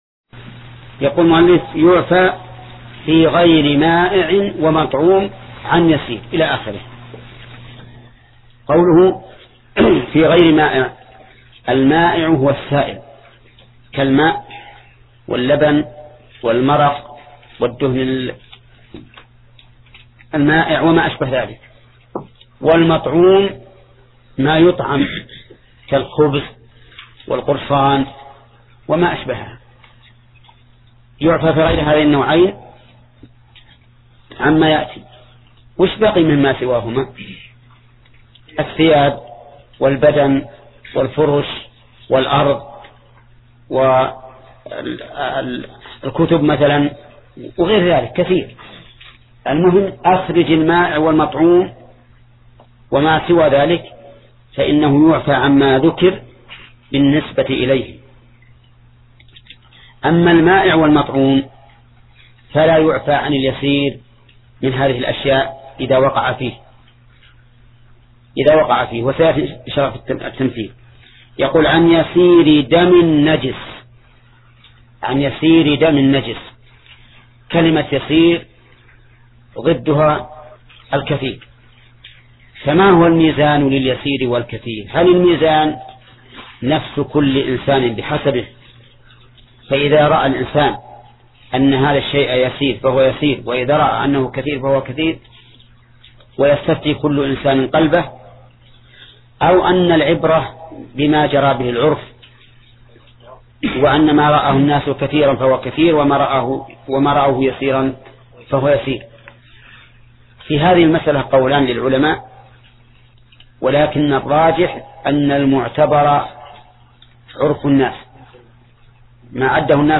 درس (22): باب إزالة النجاسة